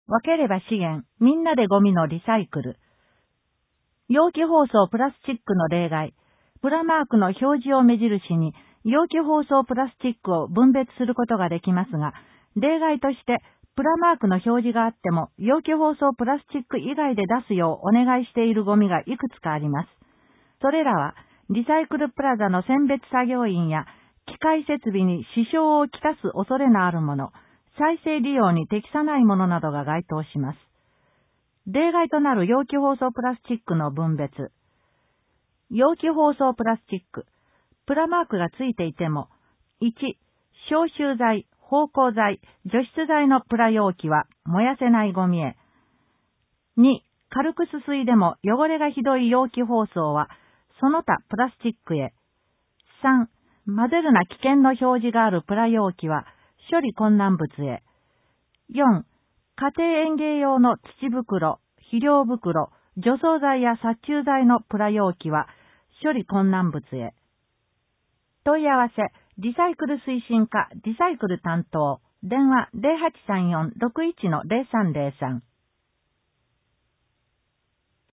音訳広報